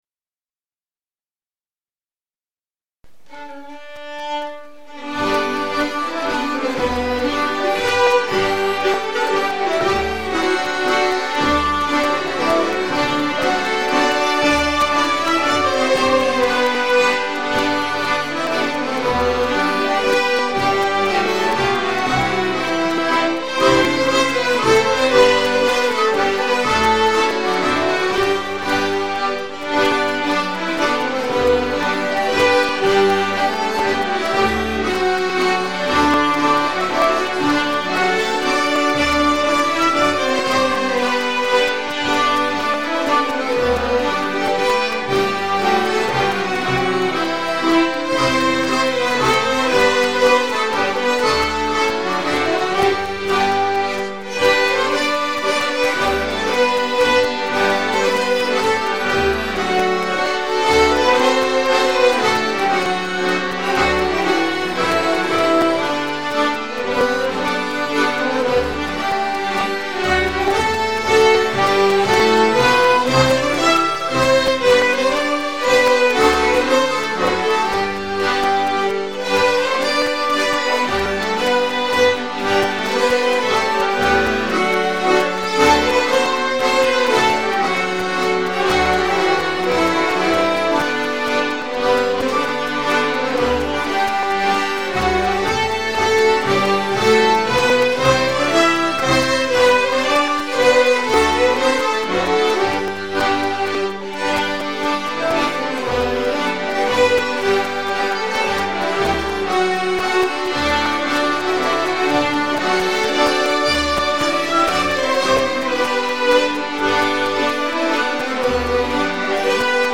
Allspelslåtar Slottsskogsstämman 2025